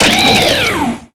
Cri de Genesect dans Pokémon X et Y.